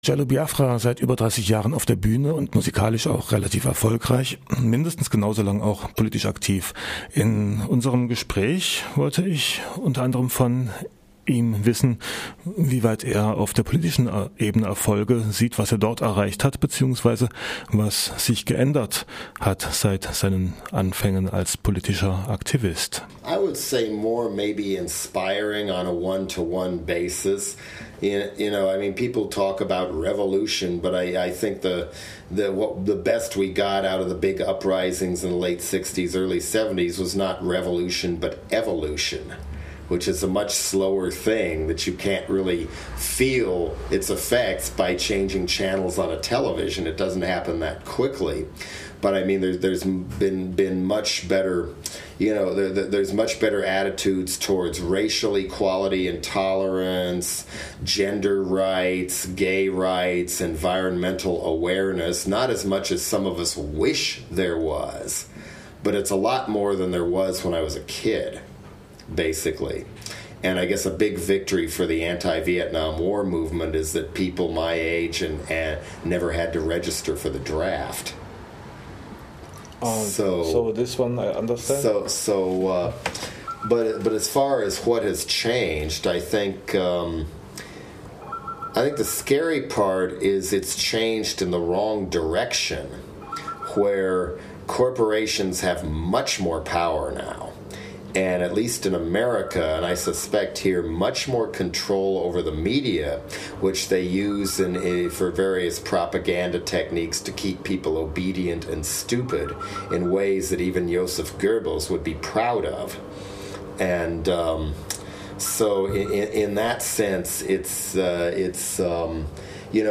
Interview mit Jello Biafra (Ausschnitt)